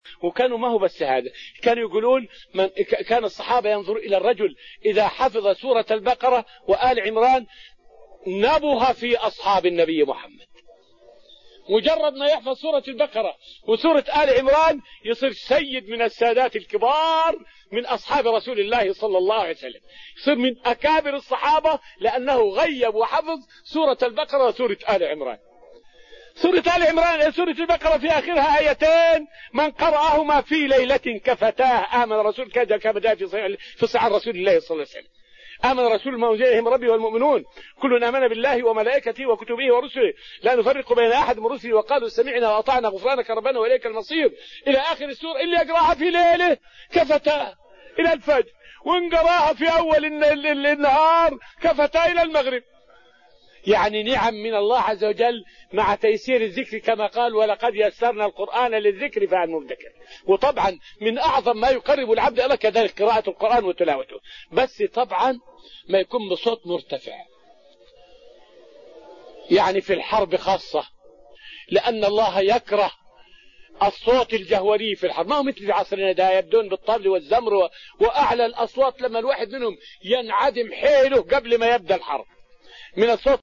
فائدة من الدرس السابع من دروس تفسير سورة الأنفال والتي ألقيت في رحاب المسجد النبوي حول علو منزلة حافظ البقرة وآل عمران عند الصحابة.